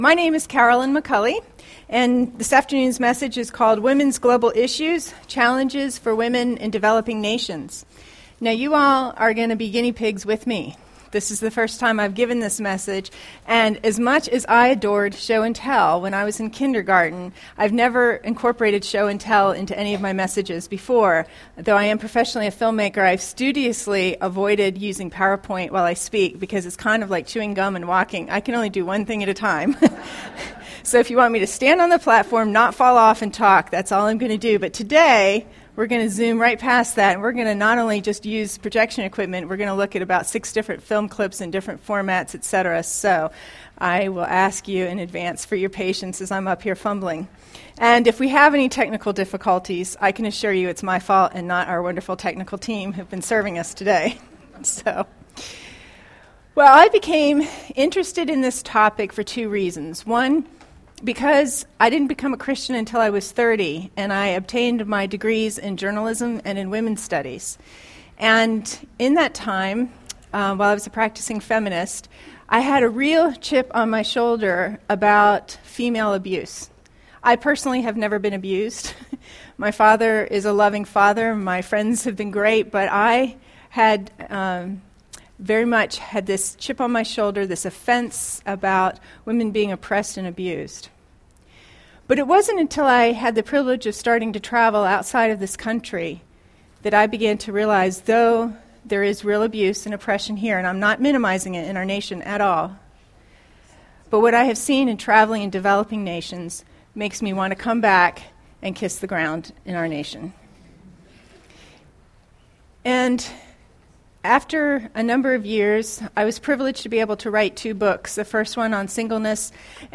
This seminar looks at the health and economic challenges women in the developing world face—including sex trafficking, maternal health, fistulas, genital cutting, and more.